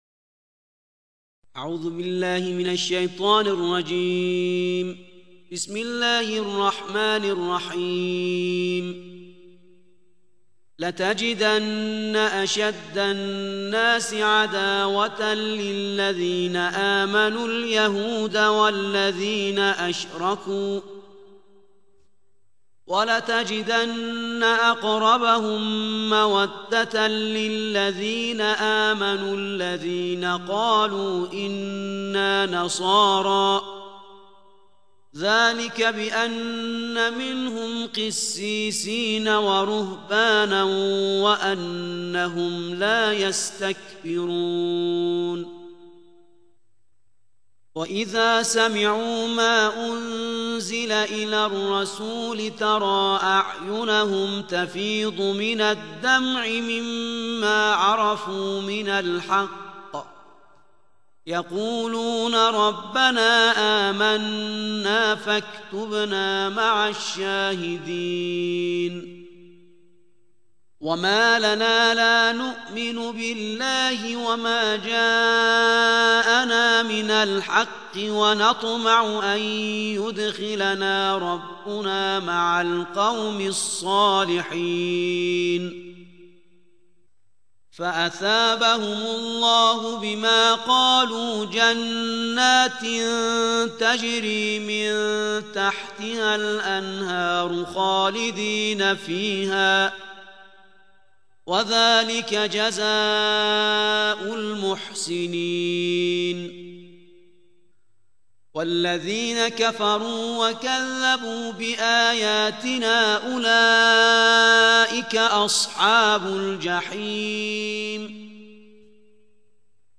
صوت تلاوت ترتیل جزء هفتم قرآن کریم